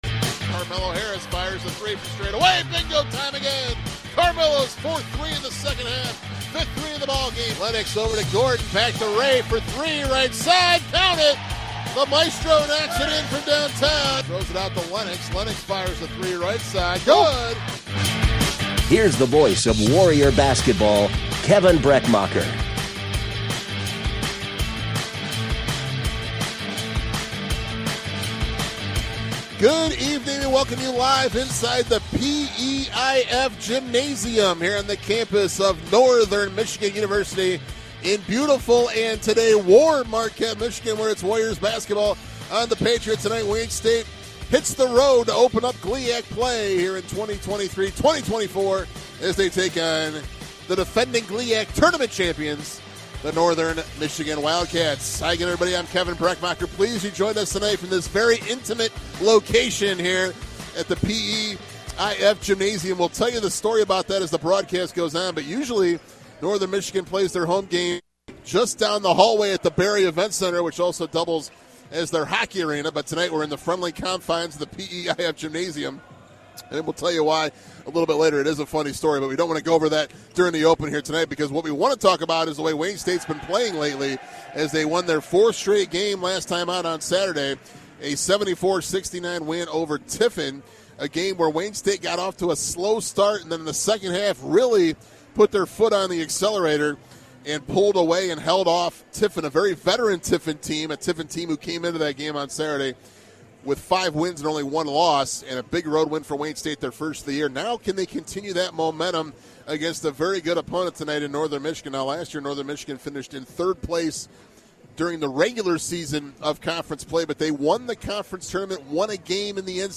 WDTK Broadcast of Men's Basketball at Northern Michigan - Dec. 7, 2023